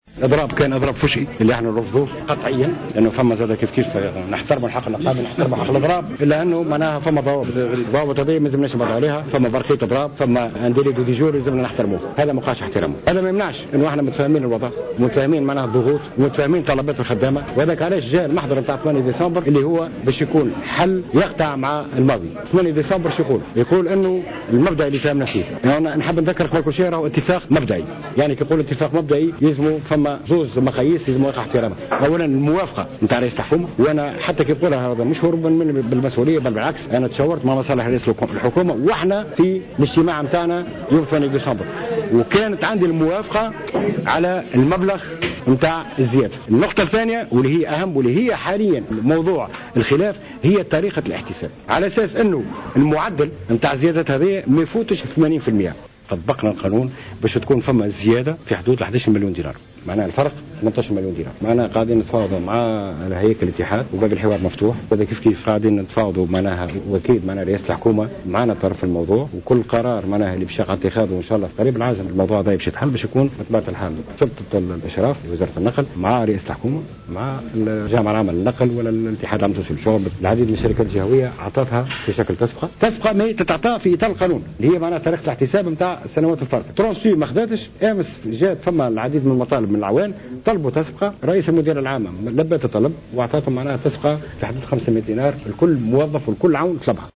Le ministre du transport, Chiheb Ben Ahmed, a exprimé, lors d’une conférence tenue aujourd’hui, son refus de la grève ouverte observée par les agents de transport.